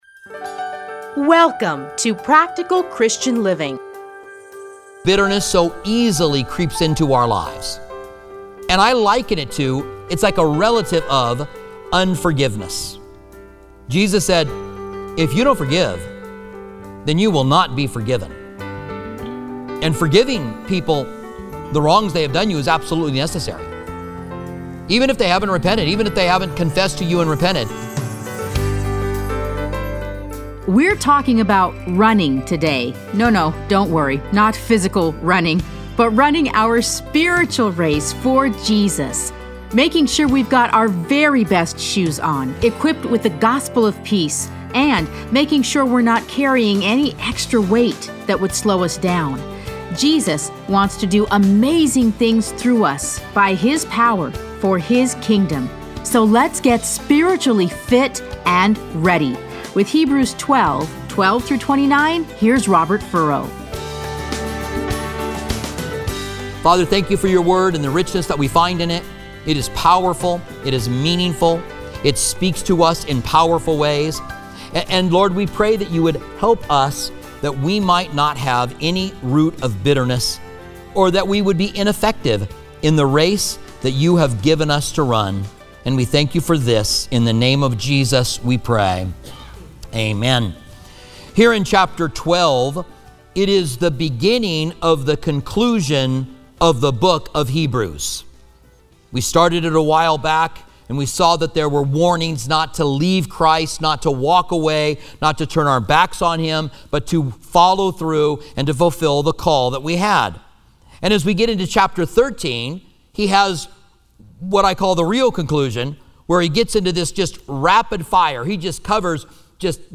Listen to a teaching from Hebrews 12:12-29.